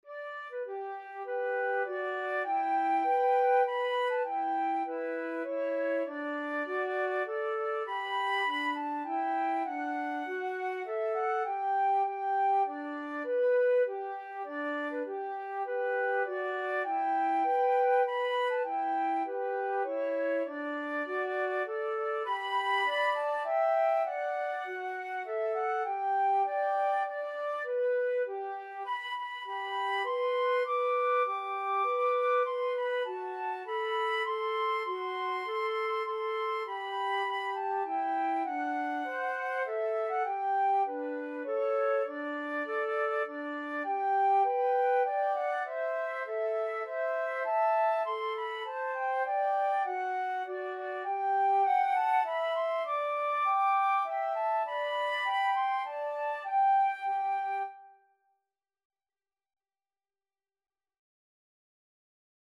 3/4 (View more 3/4 Music)
G major (Sounding Pitch) (View more G major Music for Flute Duet )
Flute Duet  (View more Intermediate Flute Duet Music)
Traditional (View more Traditional Flute Duet Music)